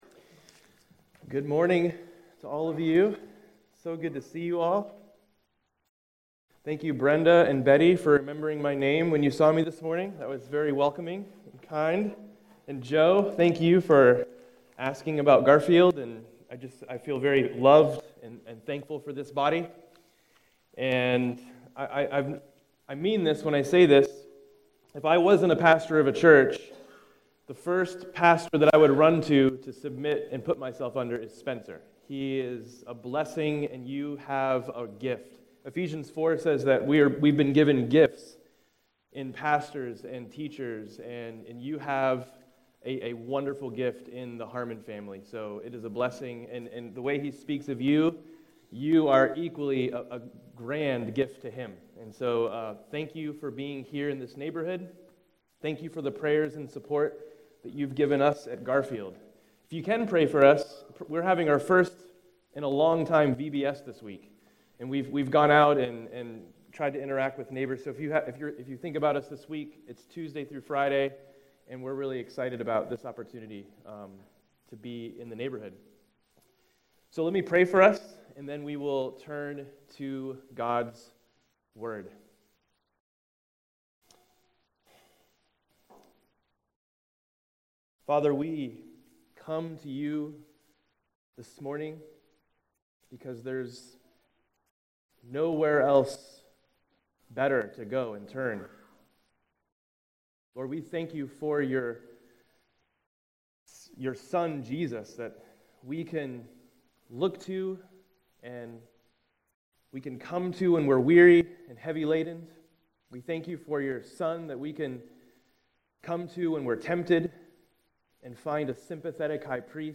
August 6, 2017 Morning Worship | Vine Street Baptist Church
After the sermon, the congregation stood and sang Turn Your Eyes Upon Jesus during a time of reflection.